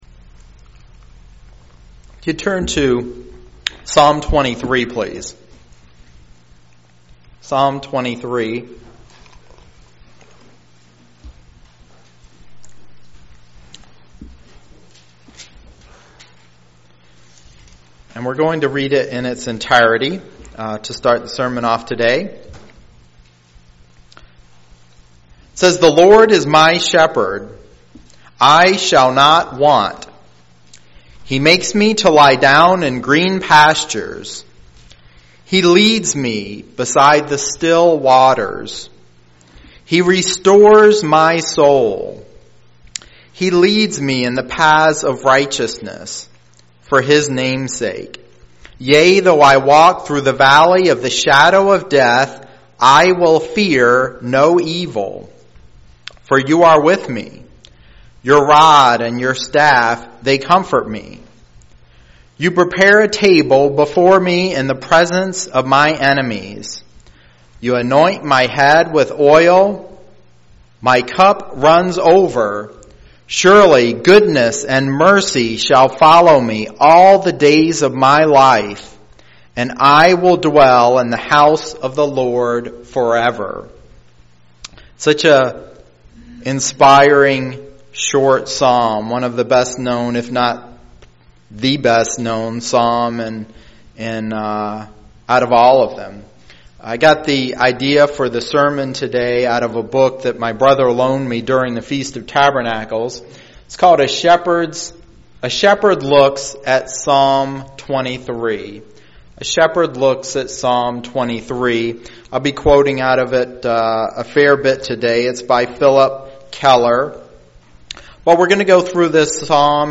Print A study of how Christ shepherds the Church UCG Sermon Studying the bible?
Given in Lansing, MI